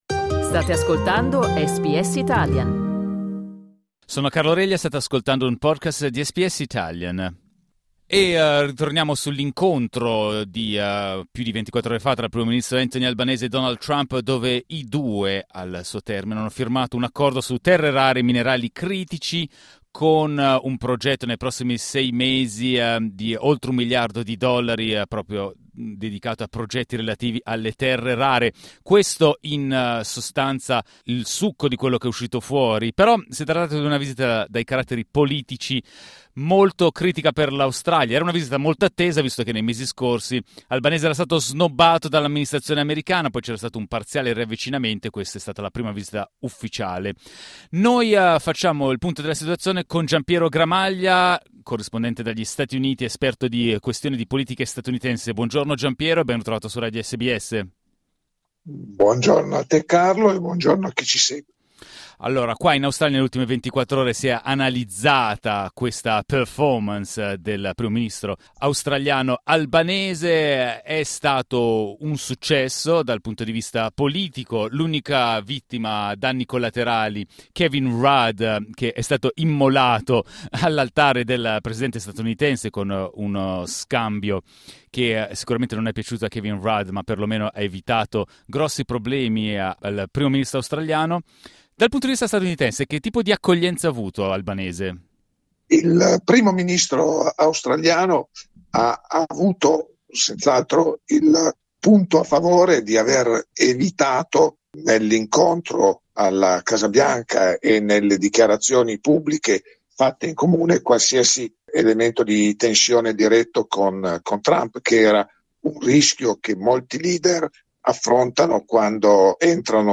Un incontro valutato come un successo da parte australiana, ma come è stato interpretato negli Stati Uniti? Ascolta l'analisi del giornalista